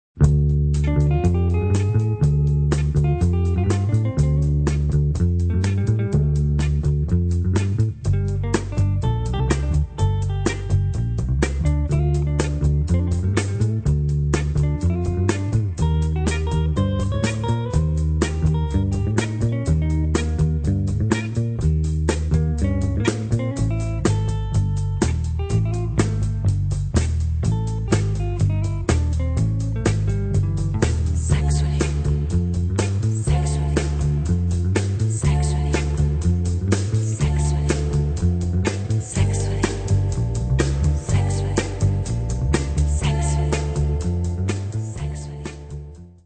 sexy medium instr.